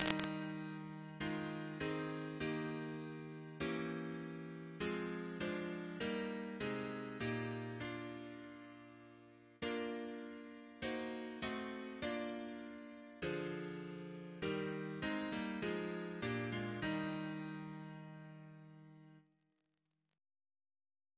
Double chant in E♭ Composer: Sir John Goss (1800-1880), Composer to the Chapel Royal, Organist of St. Paul's Cathedral Reference psalters: ACB: 300; OCB: 21; RSCM: 59